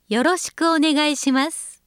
京都のホームページ作成会社が作るナレーション集